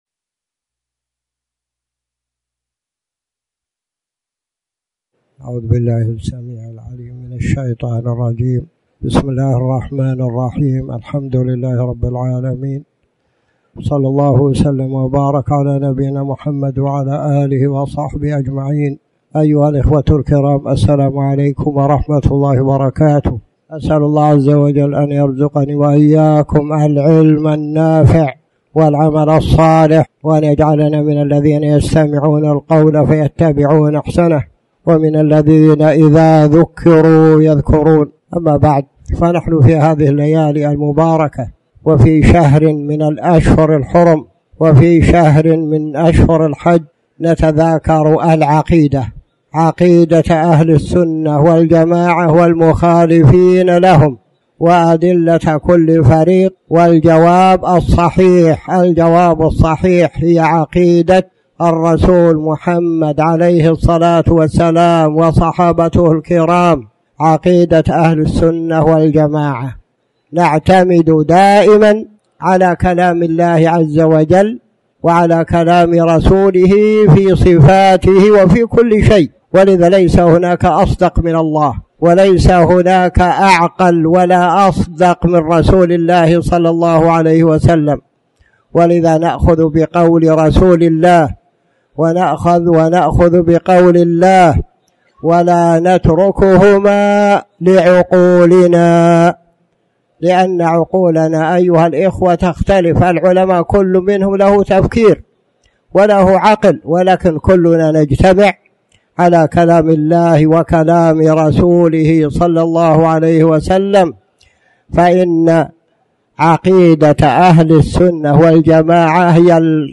تاريخ النشر ١٦ ذو القعدة ١٤٣٩ هـ المكان: المسجد الحرام الشيخ